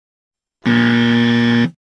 jawaban-salah.wav